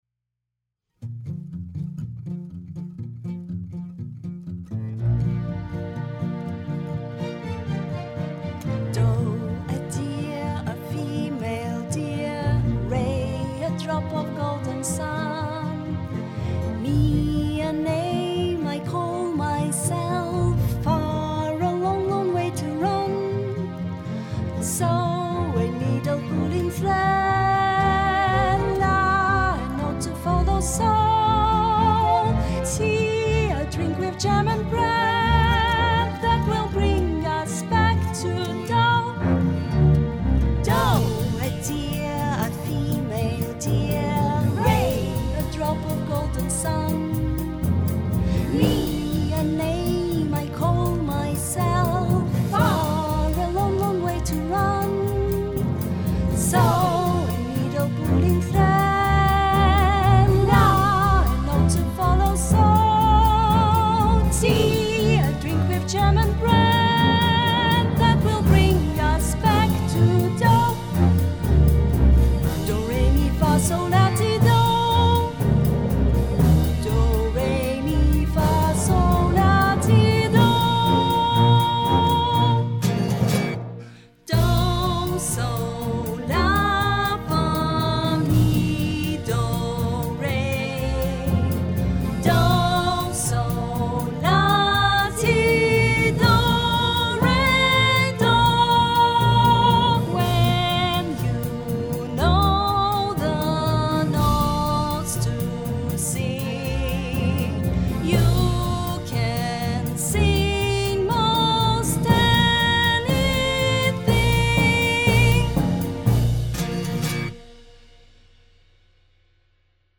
A show tune